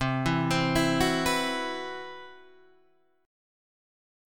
CM7b5 Chord